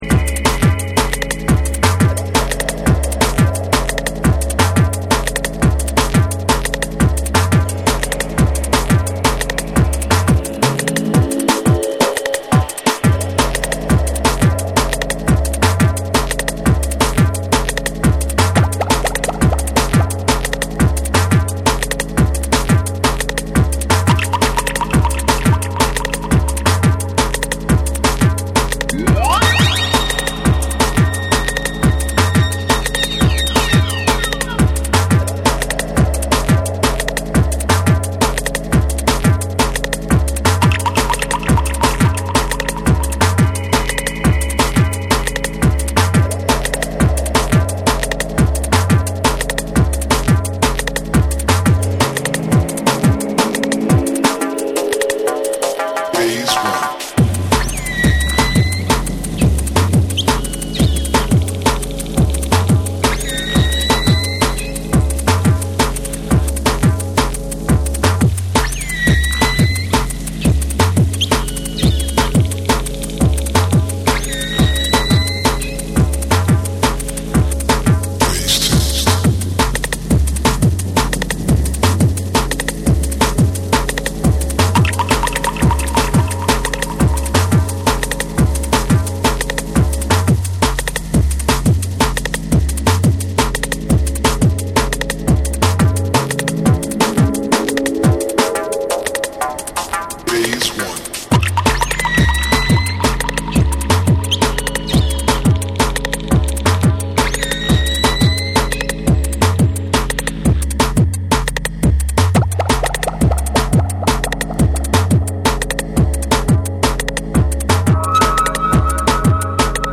浮遊感のあるシンセとタイトなドラムワークが融合し、独特のグルーヴを生み出す洗練されたドラムンベース
JUNGLE & DRUM'N BASS